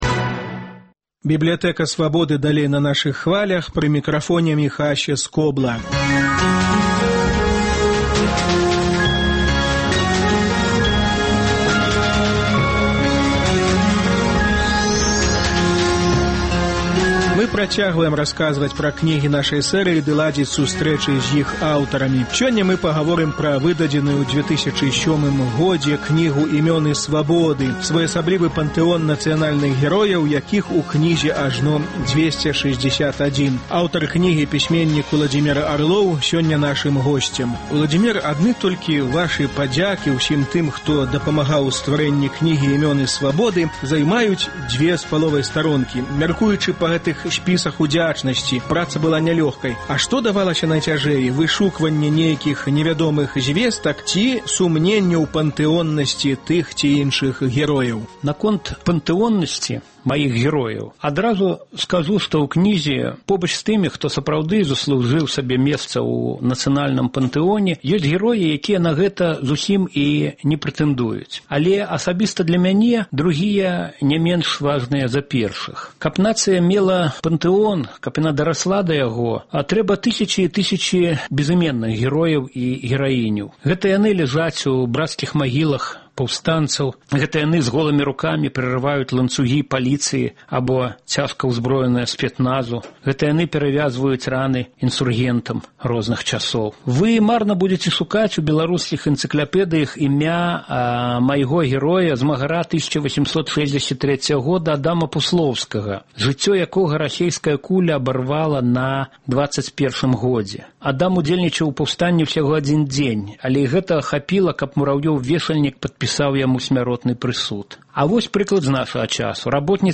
Гутарка з Уладзімерам Арловым.